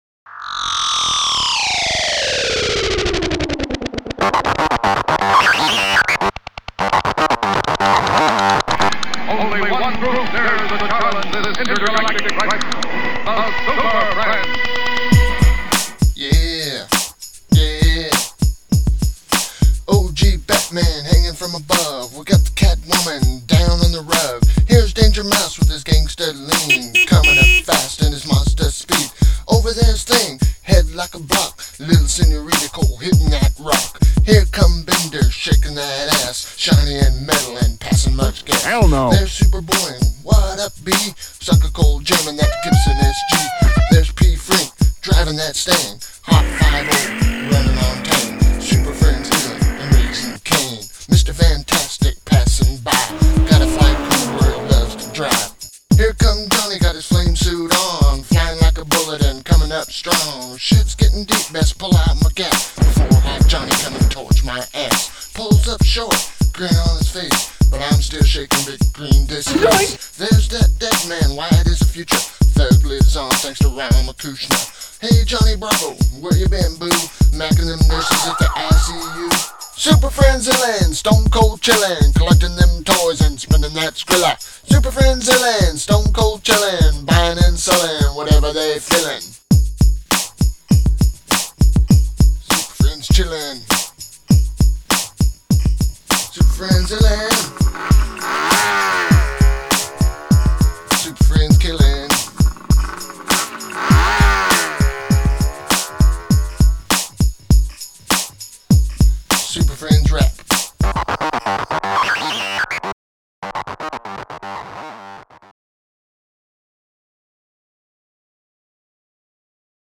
SuperFriends Rap song in mp3 format!
SFOC-Rap5.mp3